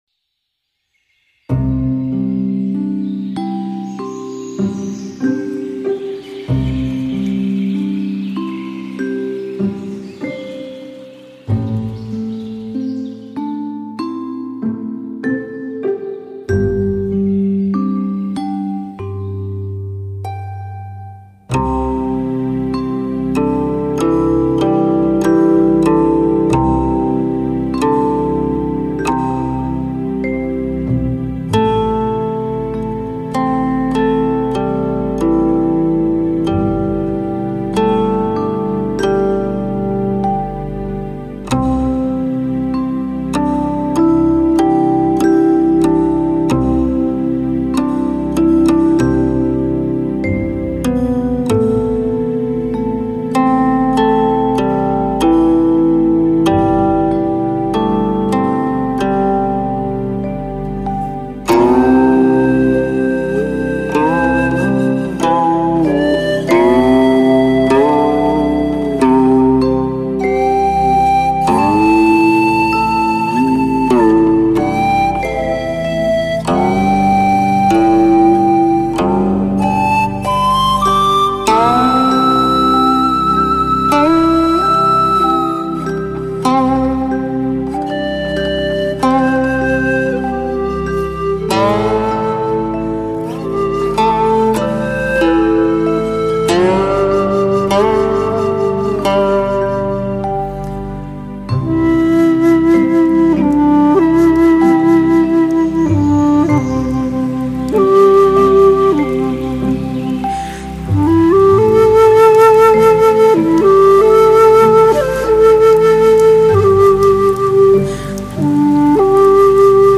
古琴演奏